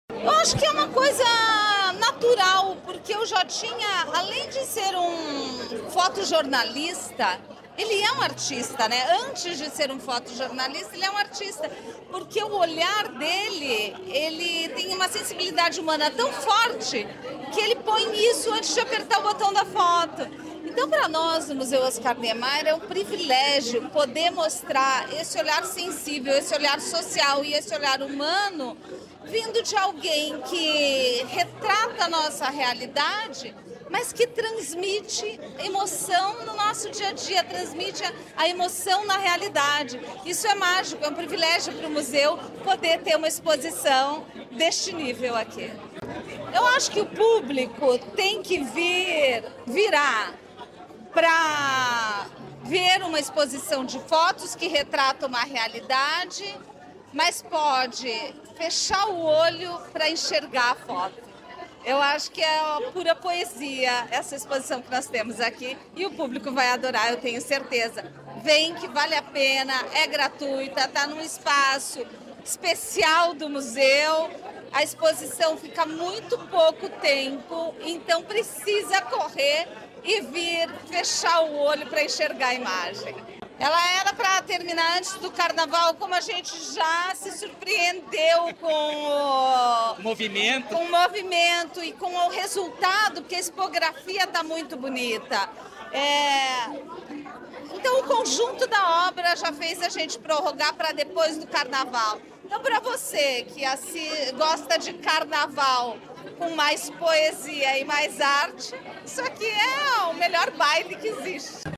Sonora da secretária da Cultura, Luciana Casagrande Pereira, sobre a abertura da mostra fotográfica “Instante do Olhar”, no MON